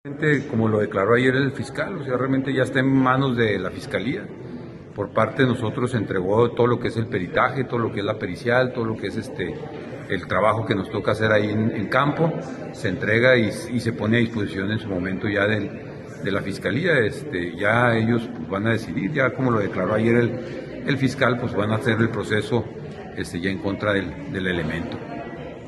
AUDIO: CÉSAR KOMABA, SUBSECRETARIO DE MOVILIDAD DE LA SECRETARÍA DE SEGURIDAD PÚBLICA DEL ESTADO (SSPE)